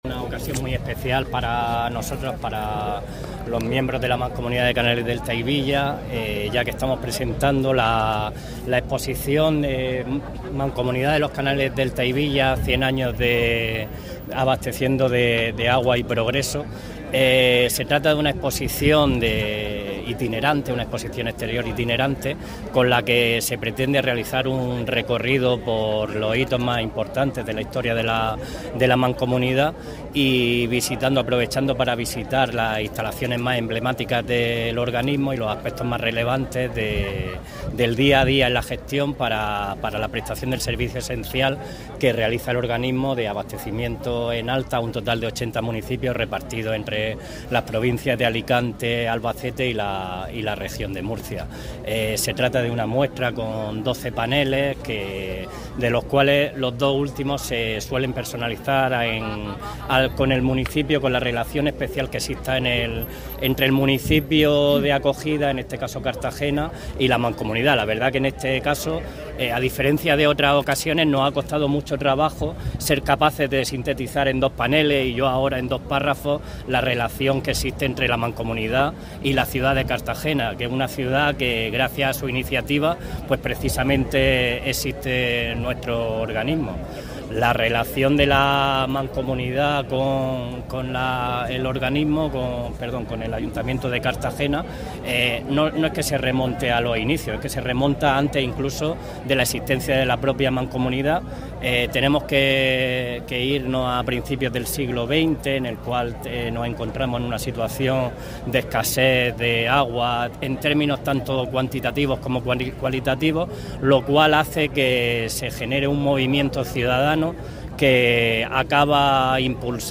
Audio: Declaraciones de la alcaldesa, Noelia Arroyo, visita a los estudiantes UPCT ganadores del Concurso de Puentes (MP3 - 689,36 KB)